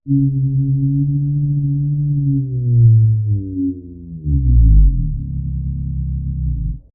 This animal senses below 20 Hz or (pulses) cycles per second.
This sound was generated with a theremin.
beached.mp3